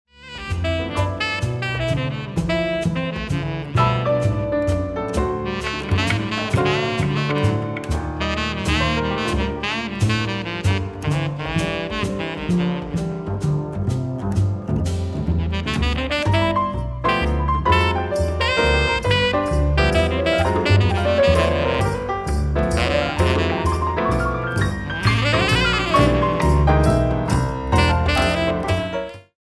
Alto Sax, Piano, Bass, Percussion
Improvisation is the cloth of these works are cut from.